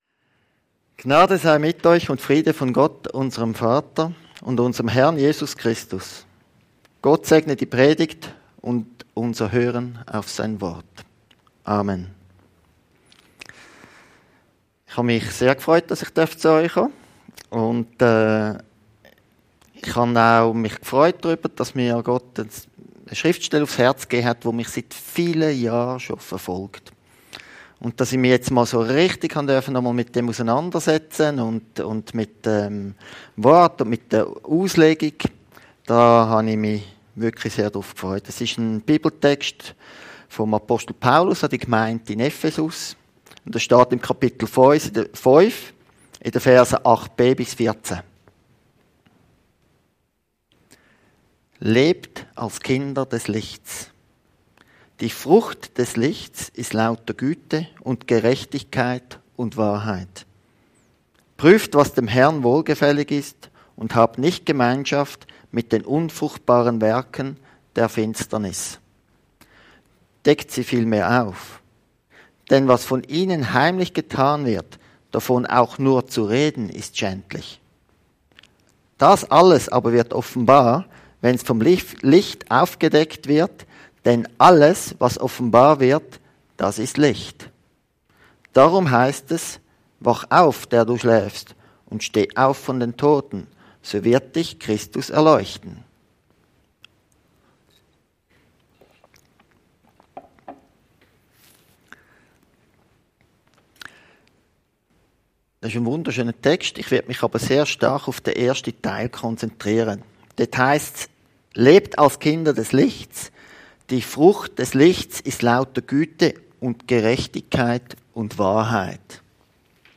Passage: Epheser 5, 8b-9 Dienstart: Abendgottesdienst , Sonntagmorgengottesdienst « NEIDISCH?